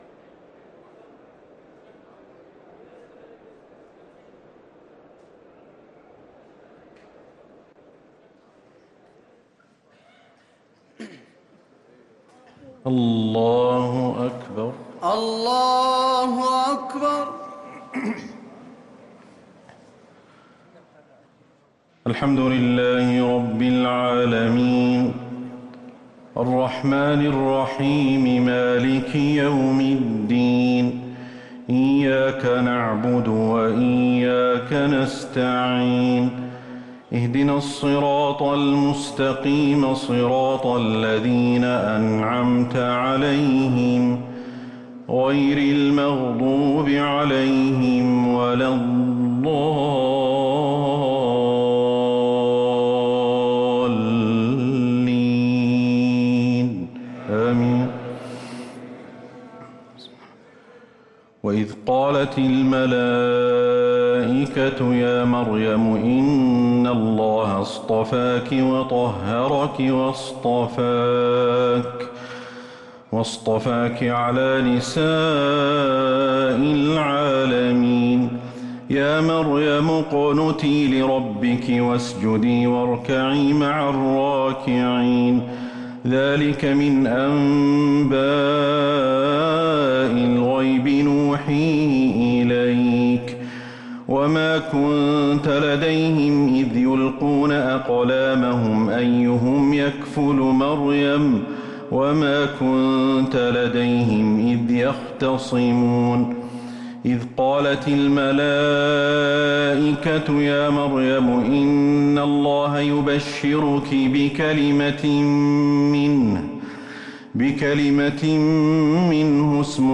صلاة التراويح
تِلَاوَات الْحَرَمَيْن .